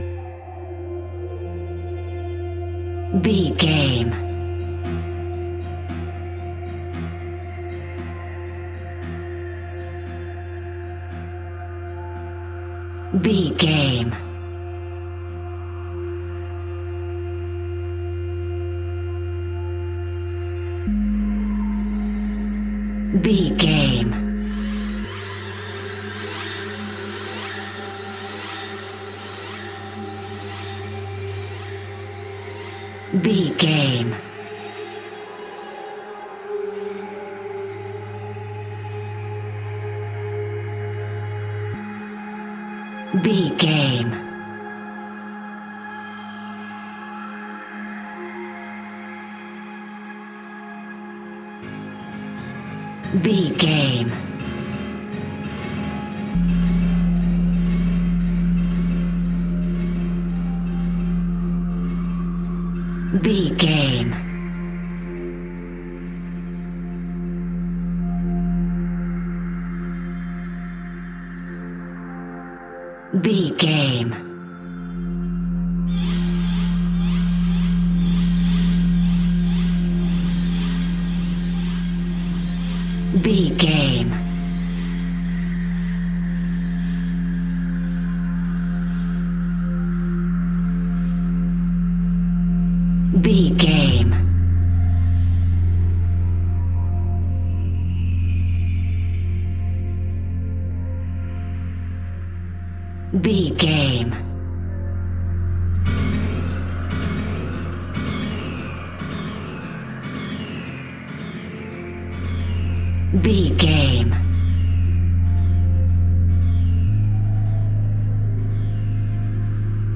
Old Horror Movie Music.
Aeolian/Minor
Slow
ominous
haunting
eerie
synthesiser
Horror Ambience
Synth Pads
Synth Ambience